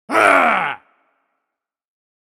Male-grunt-sound-effect.mp3